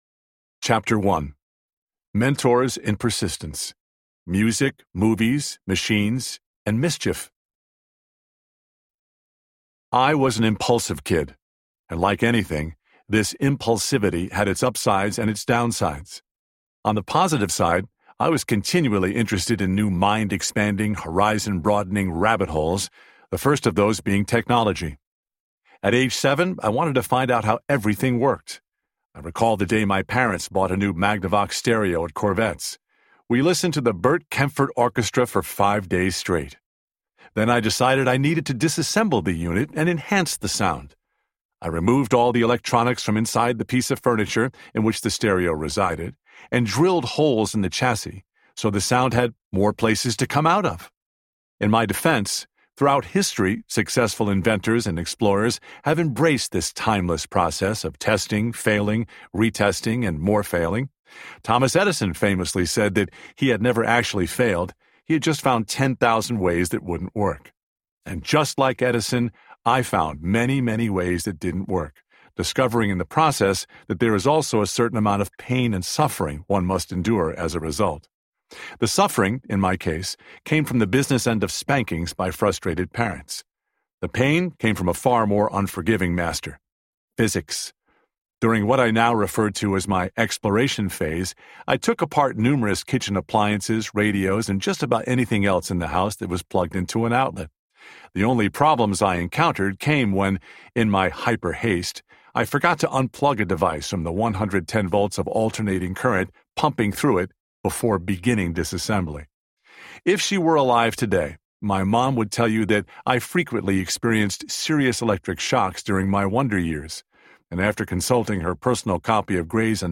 Relentless Audiobook
Narrator
John Tesh
6.4 Hrs. – Unabridged